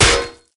CosmicRageSounds / ogg / general / combat / armor / 6.ogg